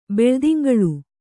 ♪ beḷdingaḷu